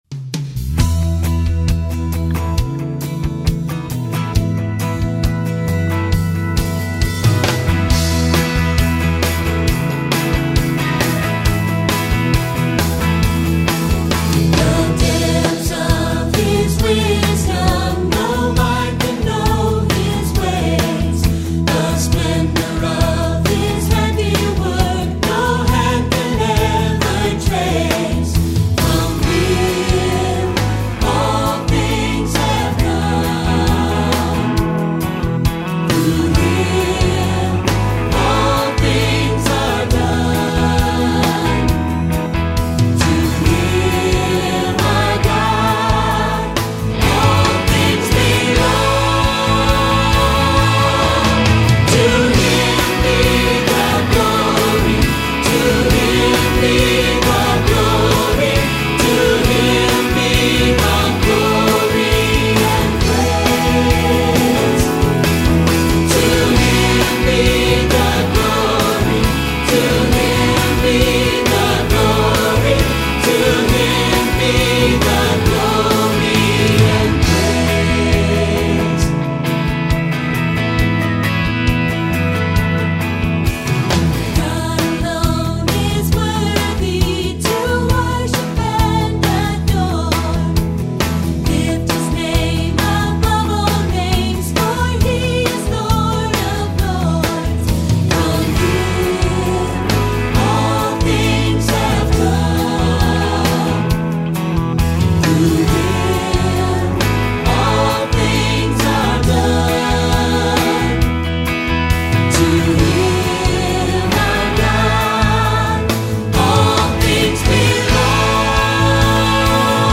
2026 Choral Selections